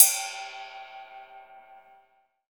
D2 RIDE-10-L.wav